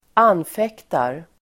Uttal: [²'an:fek:tar]